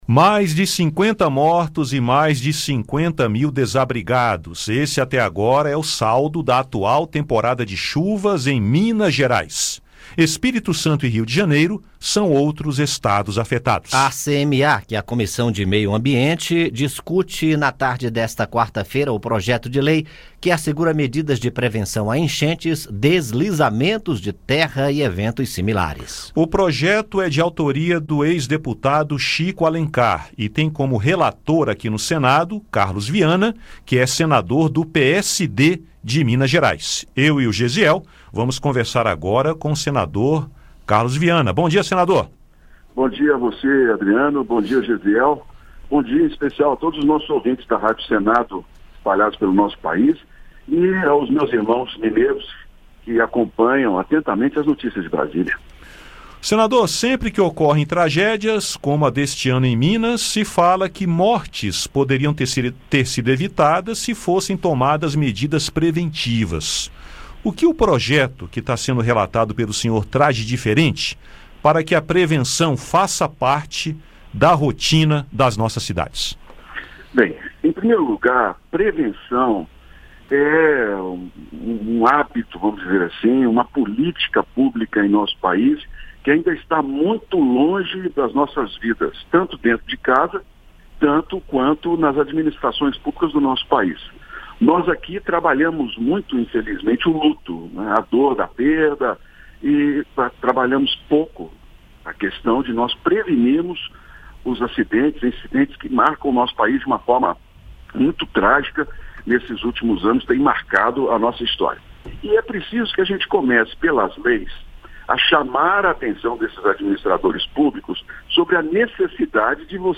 E conversamos com o relator da proposta na CMA, senador Carlos Viana (PSD-MG). Ouça o áudio com a entrevista.